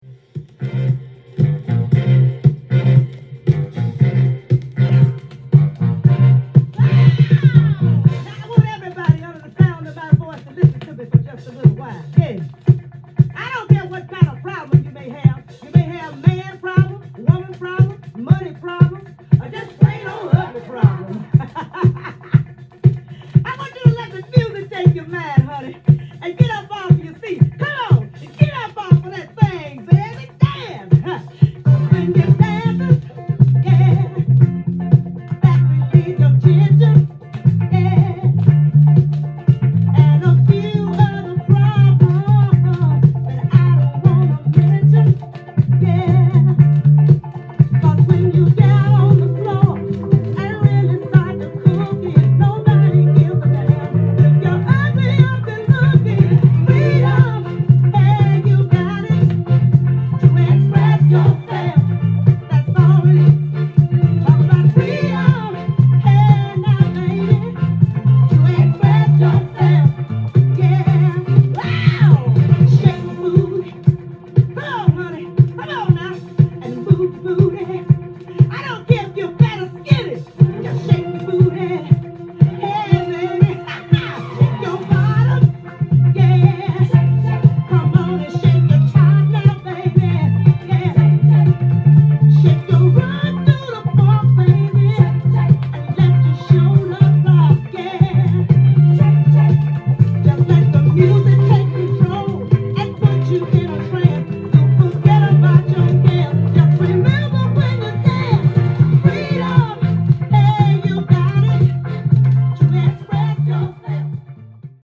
店頭で録音した音源の為、多少の外部音や音質の悪さはございますが、サンプルとしてご視聴ください。
力強いヴォーカルで歌い上げる好FUNKナンバー！！